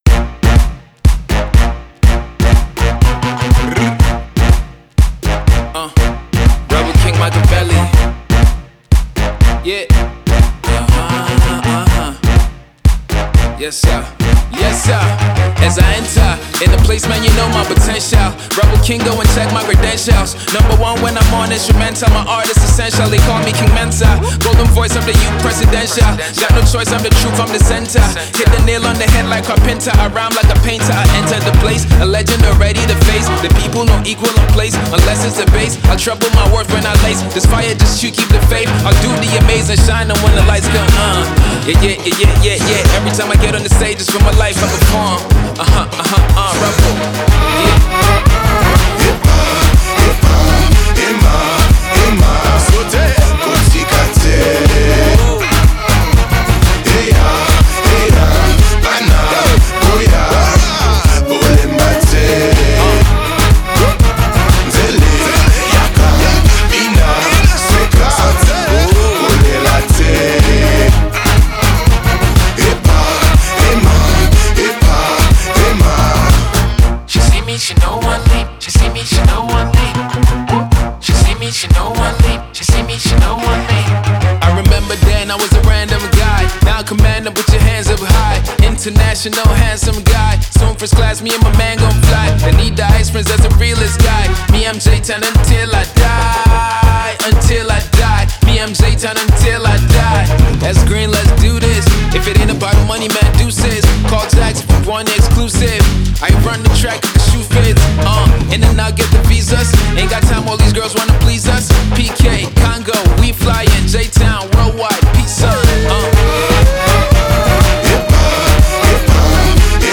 Le résultat est une musique électro et funky